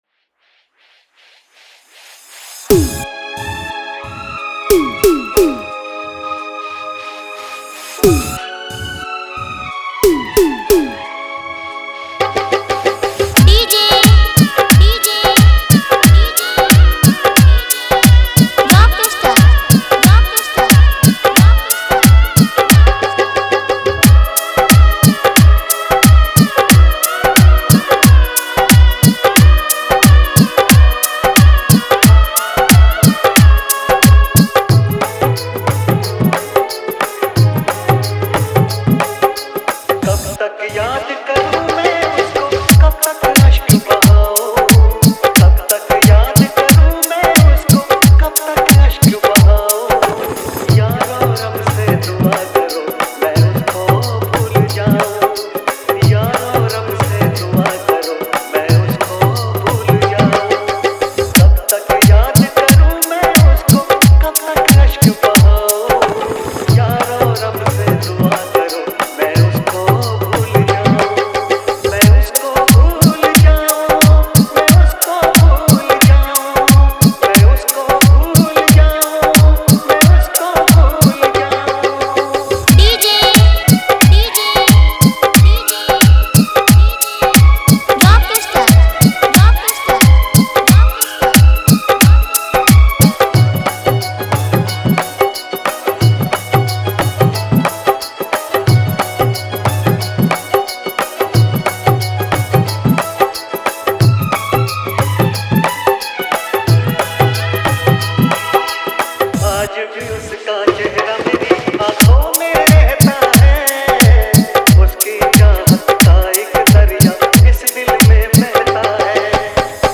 Category:  Love Dj Remix